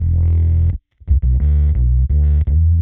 Index of /musicradar/dub-designer-samples/85bpm/Bass
DD_PBassFX_85E.wav